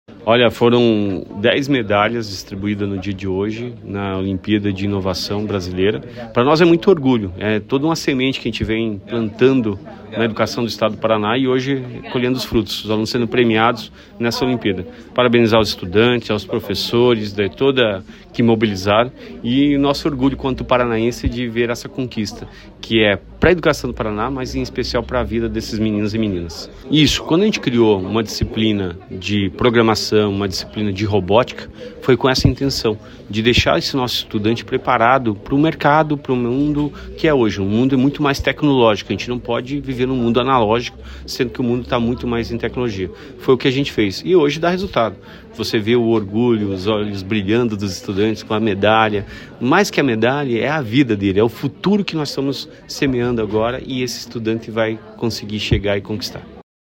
Sonora do secretário da Educação, Roni Miranda, sobre a entrega de medalhas da Olimpíada Brasileira de Inovação, Ciência e Tecnologia para alunos da rede estadual